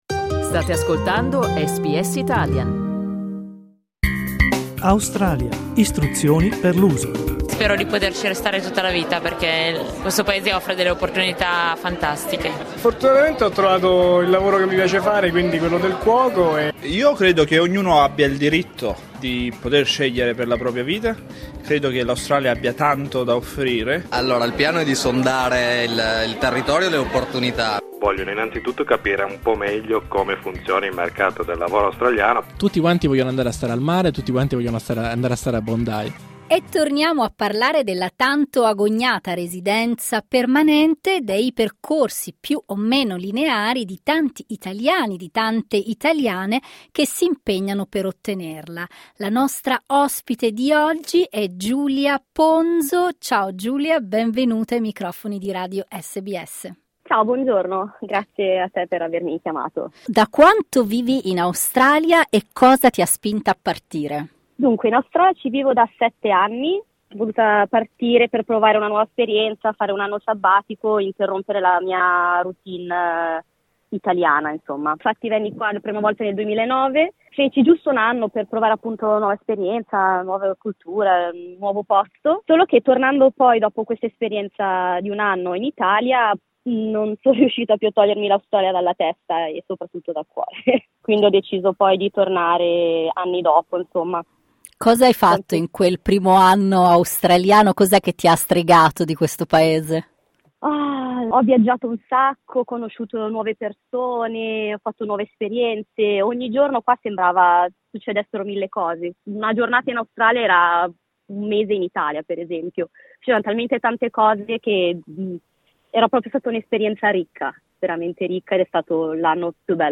Dai nostri archivi, vi riproponiamo quest'intervista dell'ottobre del 2023.